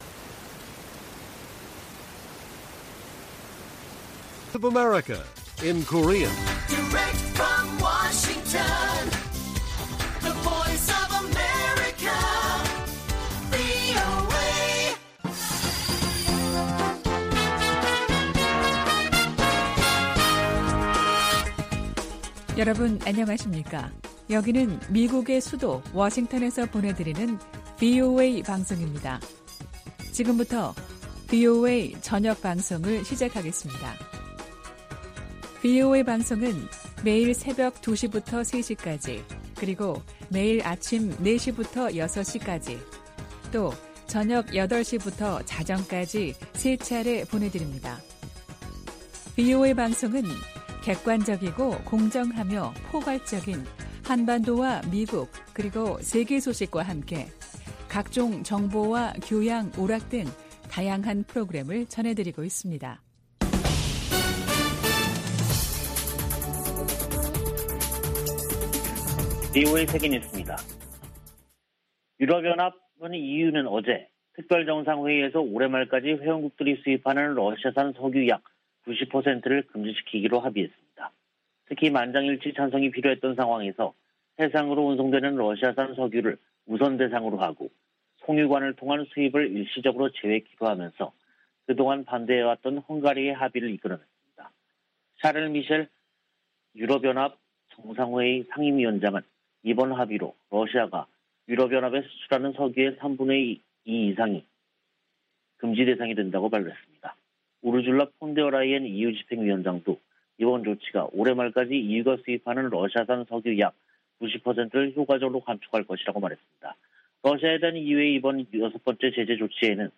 VOA 한국어 간판 뉴스 프로그램 '뉴스 투데이', 2022년 5월 31일 1부 방송입니다. 조 바이든 미국 대통령이 메모리얼데이를 맞아 미군 참전 용사들의 희생을 기리고, 자유민주주의의 소중함을 강조했습니다. 미 연방 상원의원이 메모리얼데이를 맞아 한국전쟁에서 숨진 미 육군 병장의 공로를 기렸습니다. 북한의 대표적 석탄 항구인 송림항에 이달 들어 대형 선박 8척이 드나든 것으로 나타났습니다.